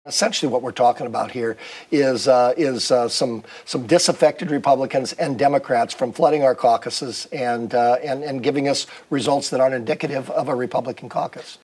during an appearance on “Iowa Press” on Iowa PBS.